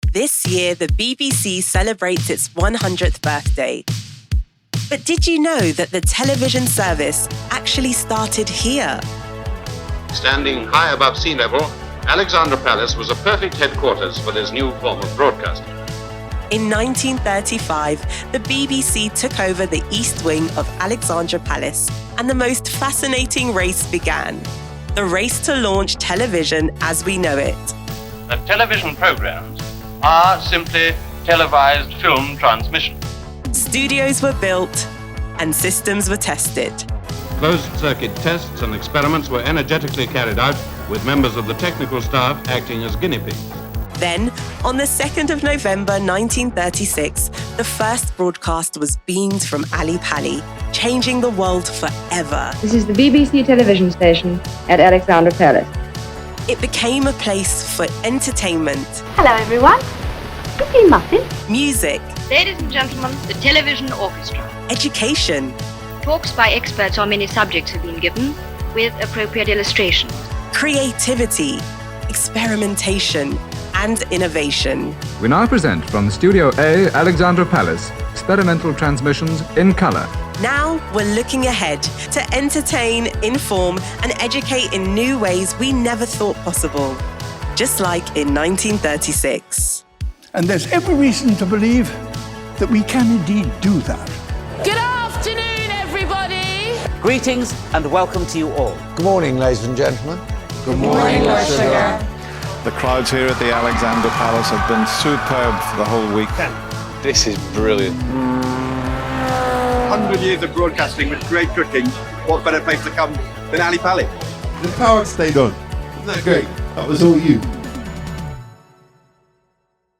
Female
Explainer Videos
All our voice actors have professional broadcast quality recording studios.